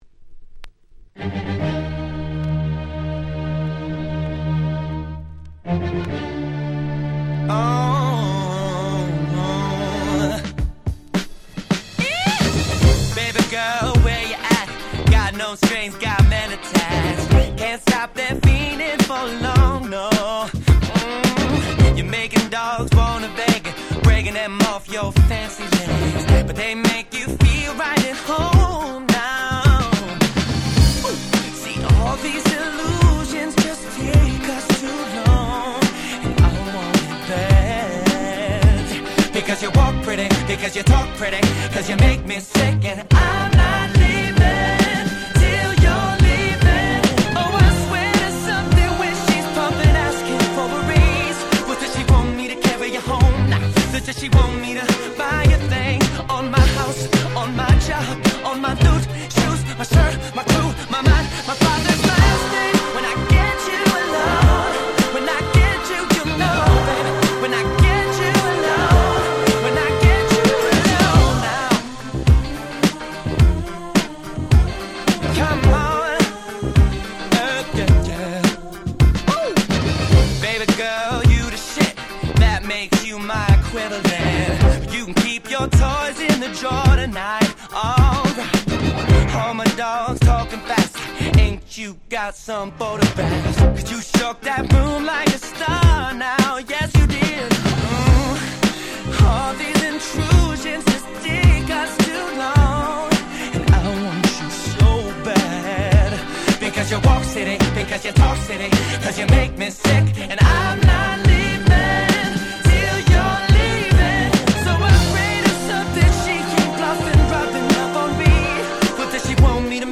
02' Smash Hit R&B !!
00's キャッチー系